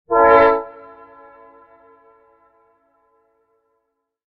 Train Horn Sound Clip
Short and powerful train horn from a distance. A single, clear and sharp blast. Transportation sounds.
Genres: Sound Effects
Train-horn-sound-clip.mp3